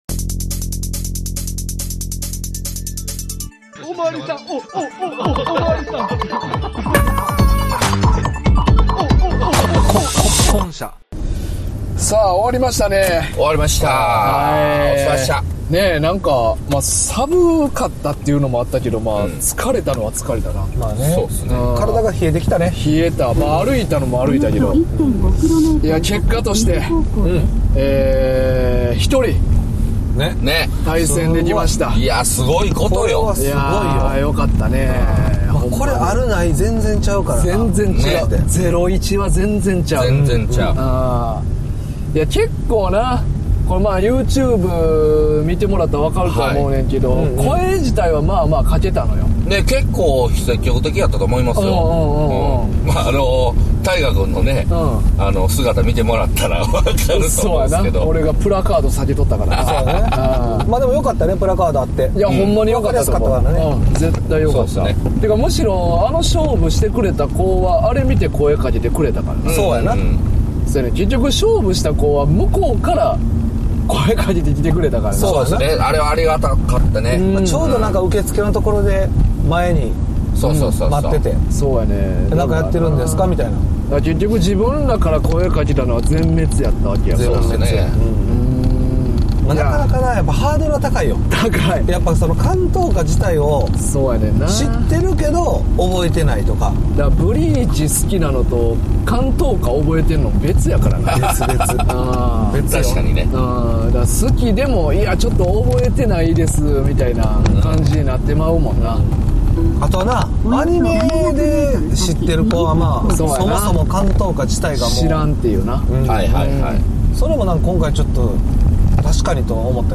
7周年記念動画を撮りにニジゲンノモリへ！ その帰り道です